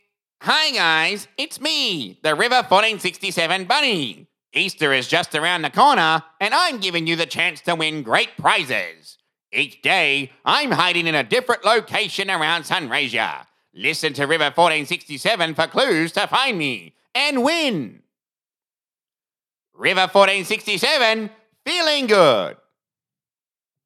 Male
Character / Cartoon